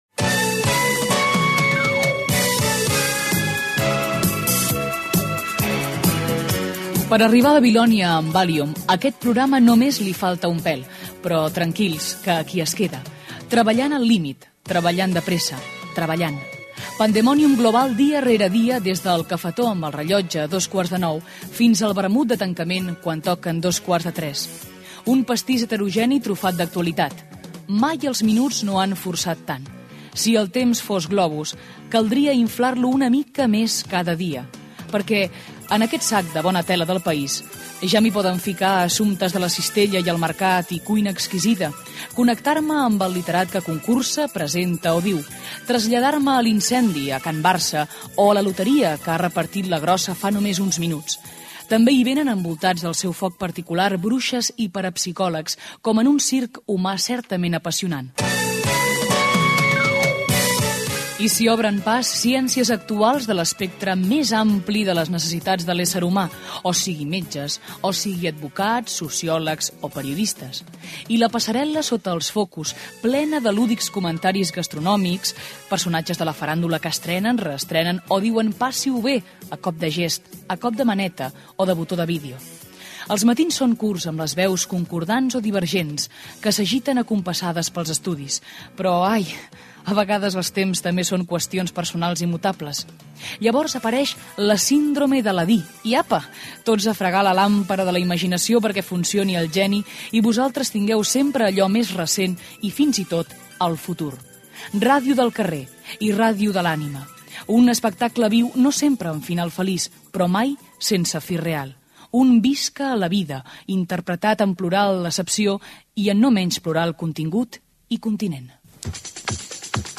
Presentació inicial i indicatiu del programa
Entreteniment